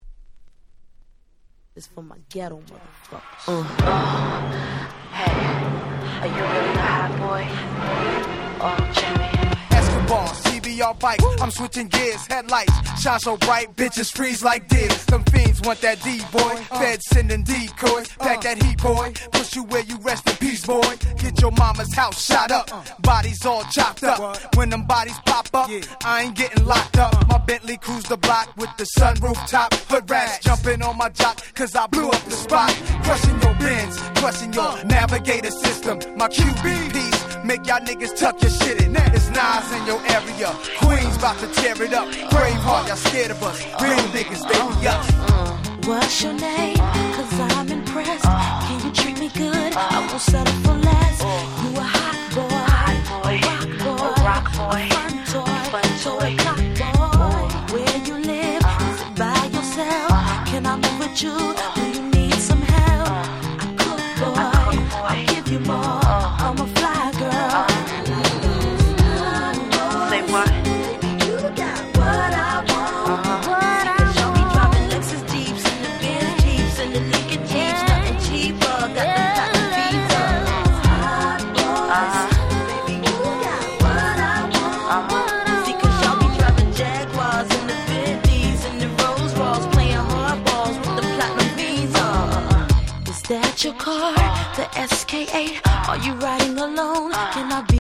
99' Super Hit Hip Hop !!
客演陣も豪華なLate 90's Hip Hop Classicsです。